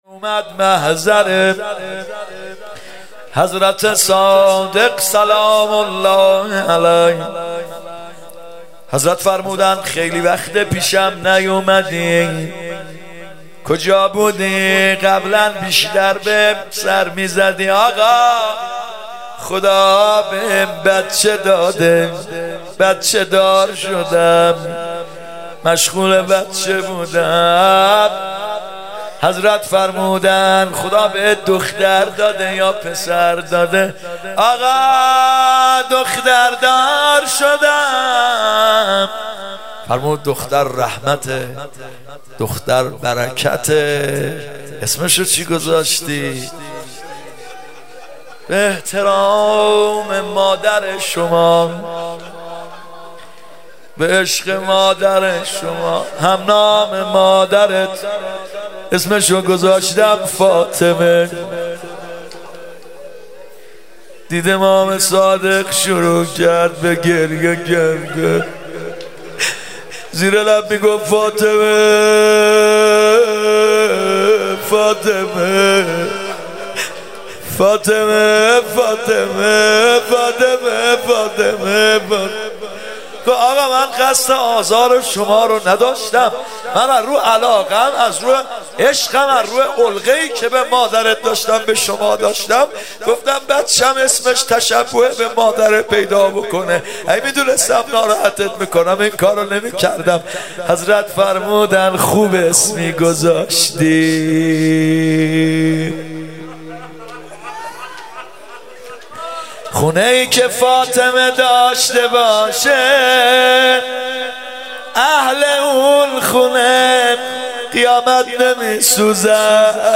محرم 95 شب پنجم روضه
محرم 95(هیات یا مهدی عج)